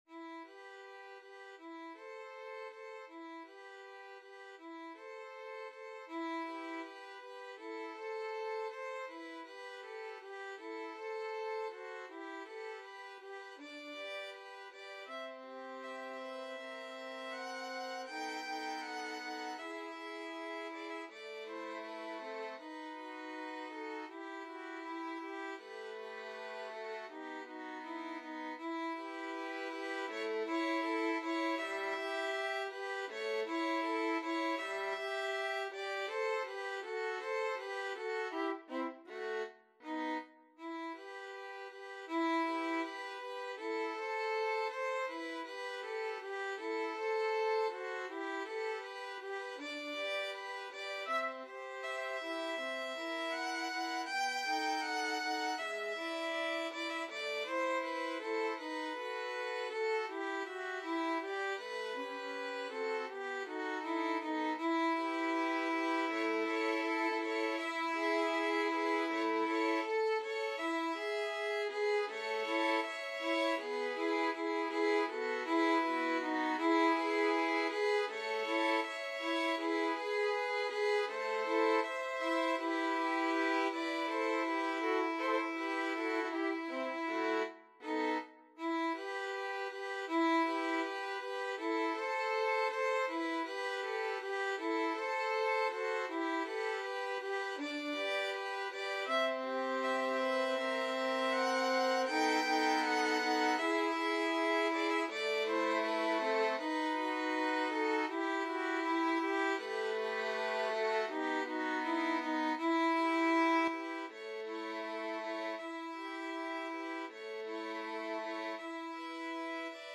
2/4 (View more 2/4 Music)
~ = 100 Andante
Violin Trio  (View more Intermediate Violin Trio Music)
Classical (View more Classical Violin Trio Music)